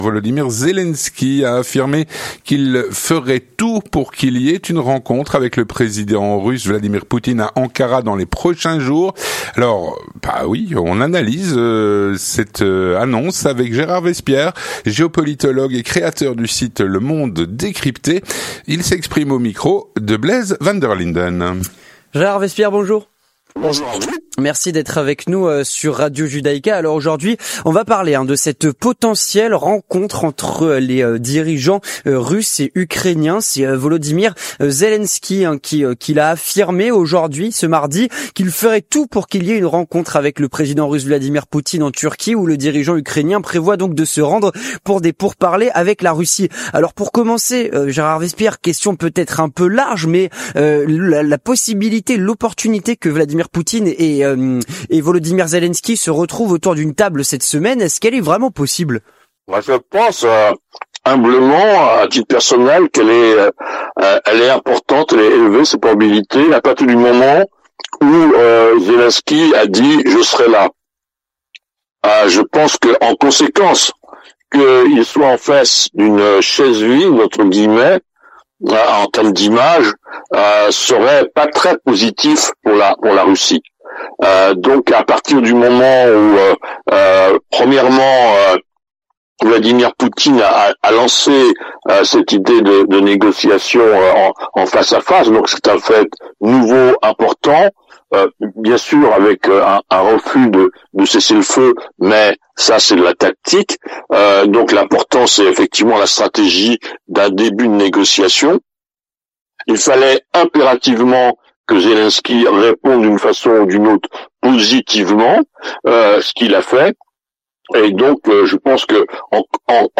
L'entretien du 18H - Une rencontre Zelensky-Poutine dans les prochains jours ?